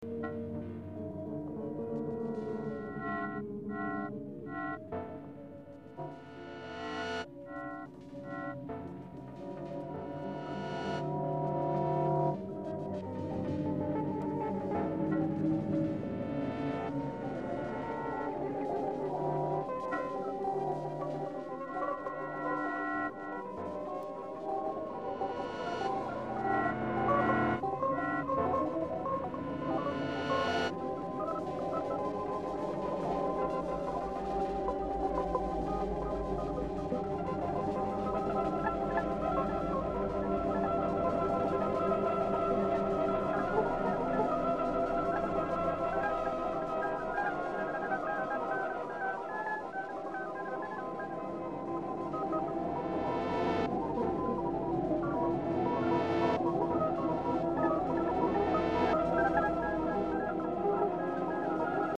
High as the Sky (electronics)
The pitch material is derived from a 014 trichord which combines with a 012 trichord in the second section. The trichords are presented in a descending form even though the overall effect is ascending pitch patterns. The minor 2nd and major/minor 3rds in the 014 trichord expand (with the addition of the 012 trichord) to expose the Perfect 4th. Ascending fourths are also heard between various transpositions of the basic set. The third (last) section is reminiscent of the first section and provides an example of cyclic processes used throughout the piece.
Serial procedures such as segmentation and mapping dominate the construction of the composition as well as providing interrelationships between the sound emanating from the two speakers. The piece was produced using electronic generating, sampling, and processing equipment housed in the Northwestern University Electronic Music Studios in Evanston, Illinois.